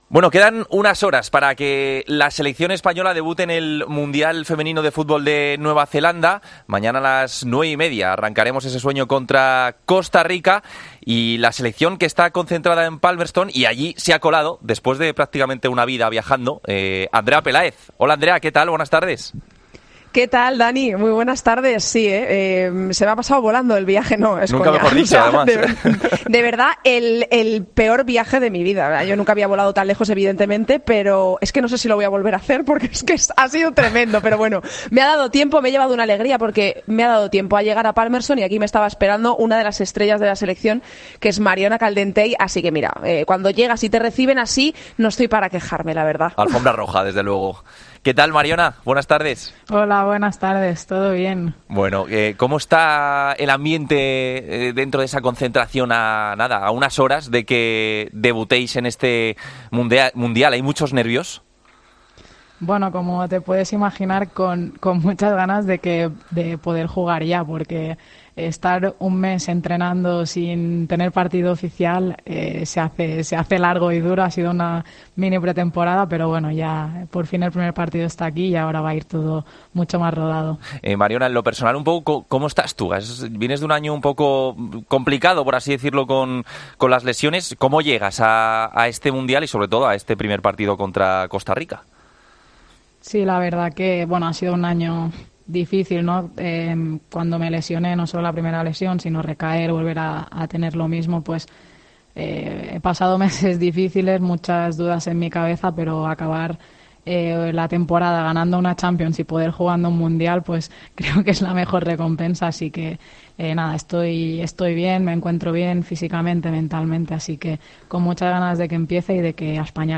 ENTREVISTA EN DEPORTES COPE
Hablamos con la jugadora del Barcelona horas antes del debut de España en el Mundial: "No seré yo la que ponga límites a esta selección, venimos con las expectativas muy altas".